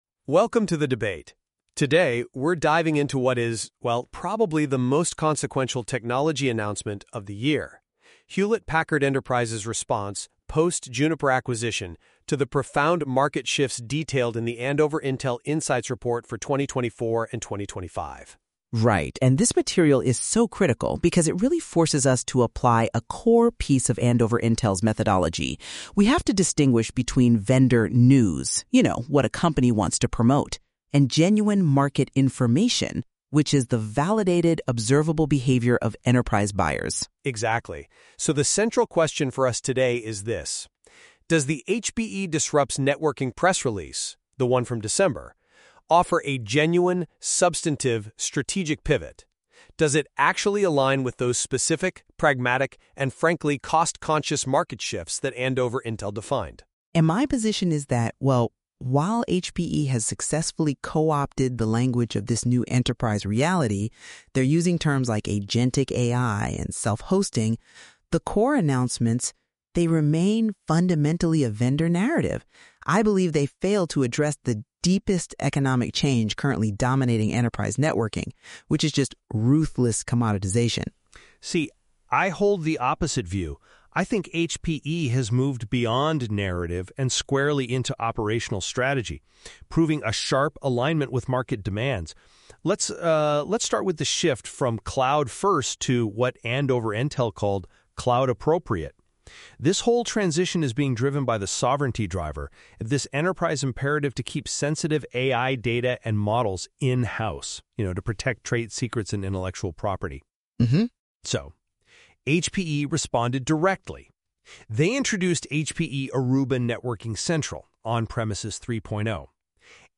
On December 3rd, at its Barcelona Event, HPE made some announcements that it said would disrupt networking. This AI-generated debate looks at the press release and compares what HPE said to the Andover Intel view of the market, synthesized from two years of our blogs generated by Google’s Gemini Deep Research.